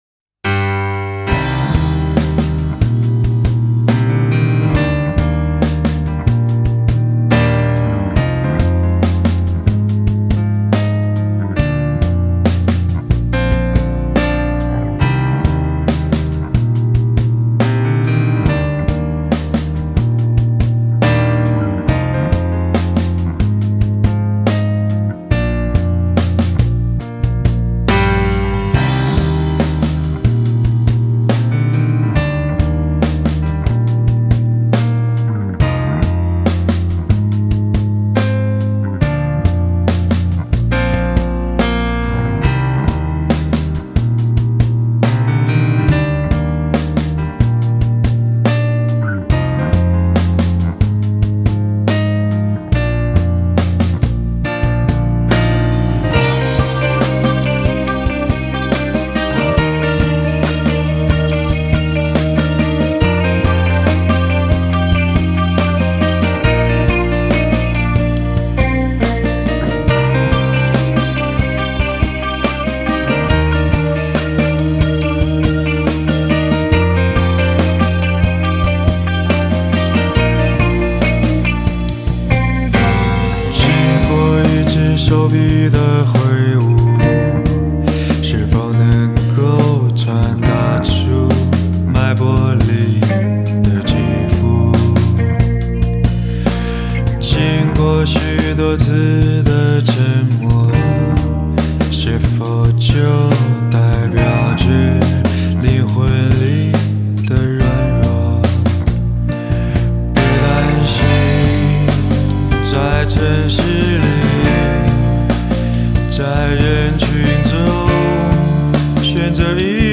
风格：华语流行